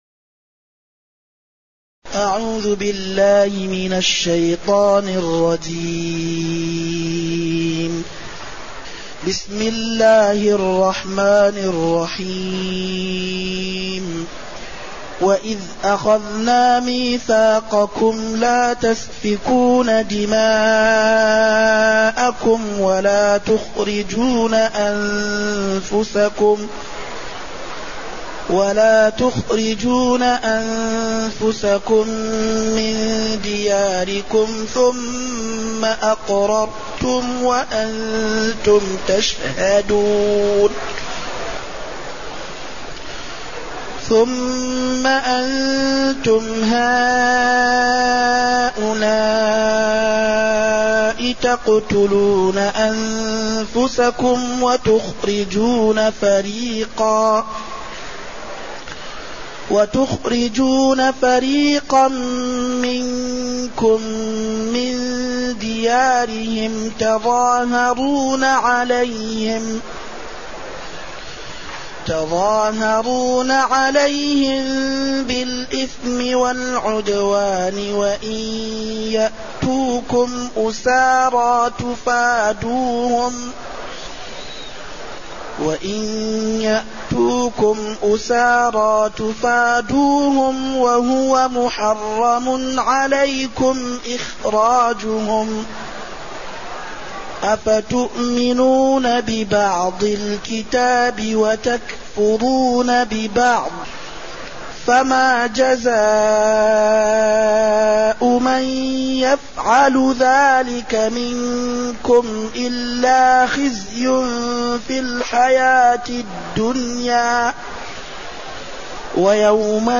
تاريخ النشر ٢٤ محرم ١٤٢٨ هـ المكان: المسجد النبوي الشيخ